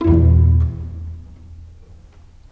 Rock-Pop 09 Pizzicato 03.wav